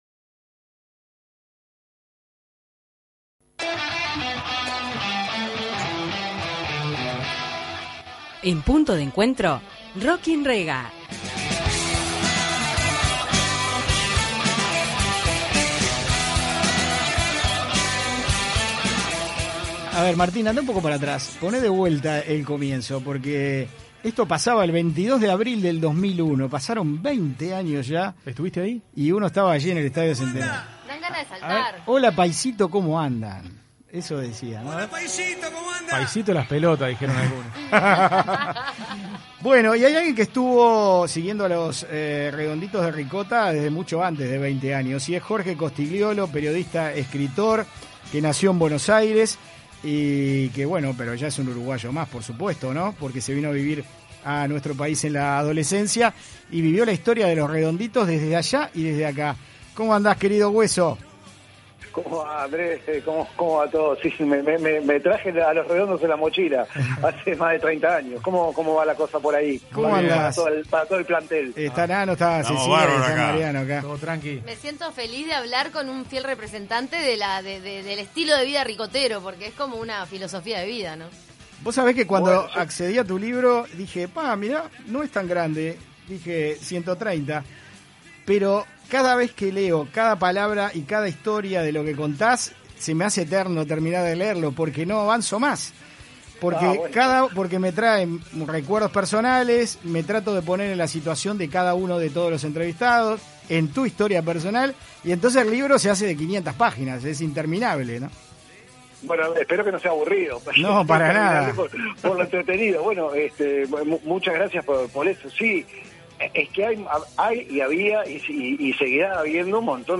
En entrevista con Rock & Rega en Punto de Encuentro